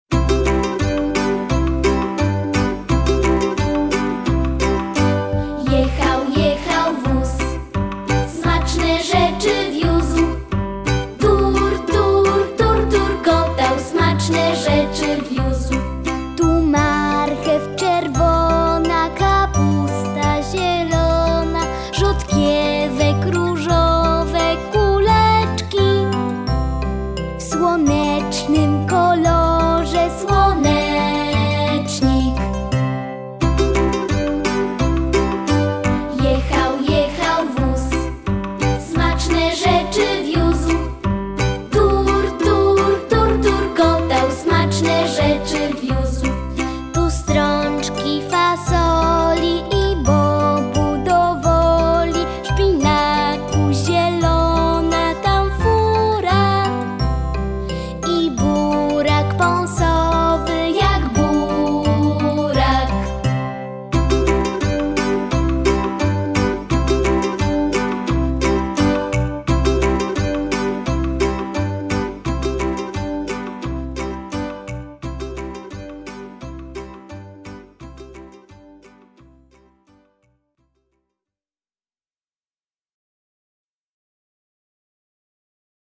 Gatunek: Alternatywna.